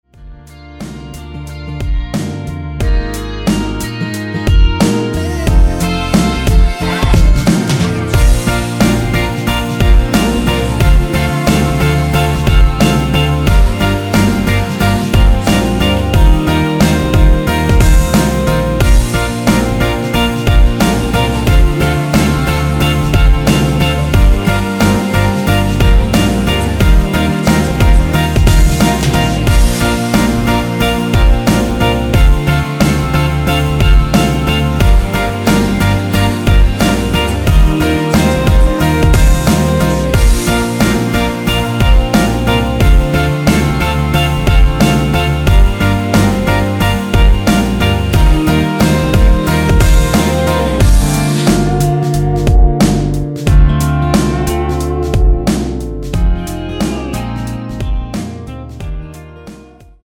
원곡의 보컬 목소리를 MR에 약하게 넣어서 제작한 MR이며
노래 부르 시는 분의 목소리가 크게 들리며 원곡의 목소리는 코러스 처럼 약하게 들리게 됩니다.